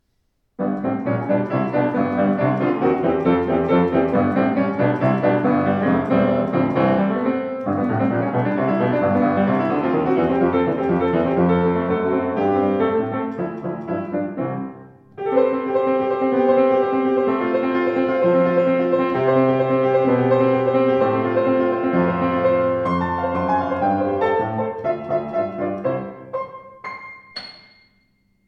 Enorm gestaltungsfähiger Klang, von warm und weich bis strahlend,